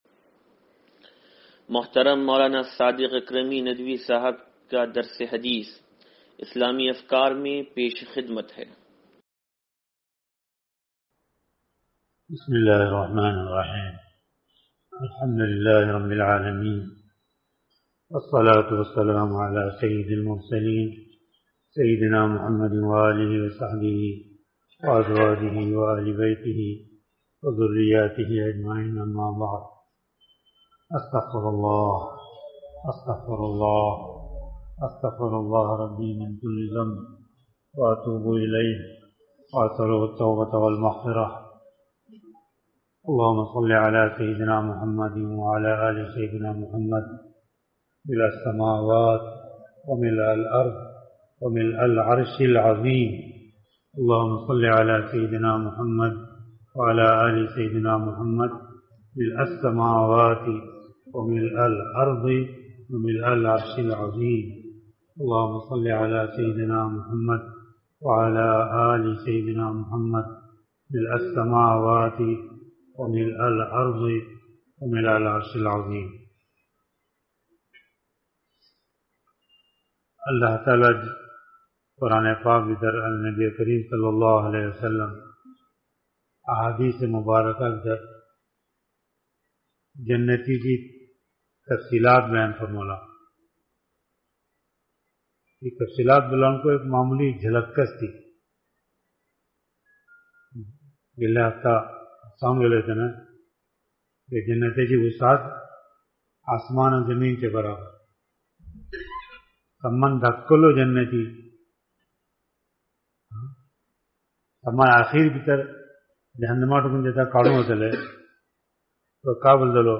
درس حدیث نمبر 0596